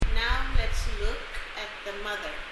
発音　　英訳：